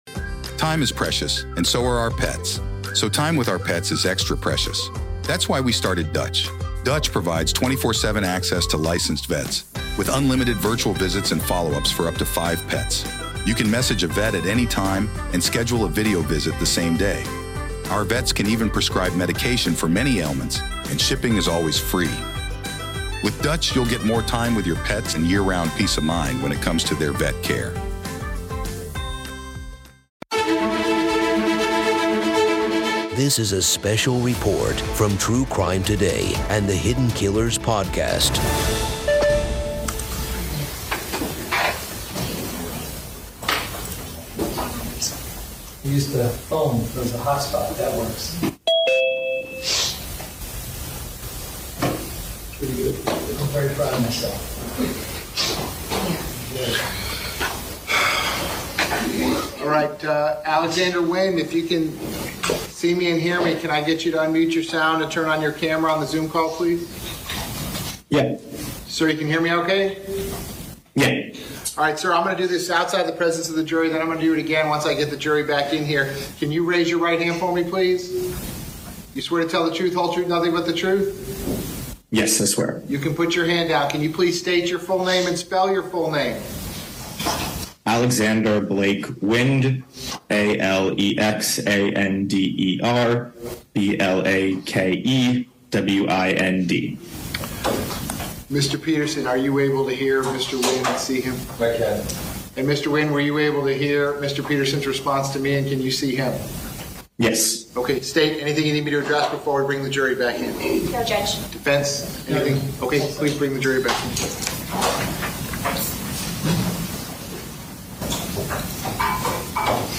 Listen as the prosecution and defense present their cases, with firsthand testimonies and critical evidence painting a gripping narrative of the tragic day. Discover the trial’s key moments and turning points and gain in-depth insights into the legal strategies and consequences.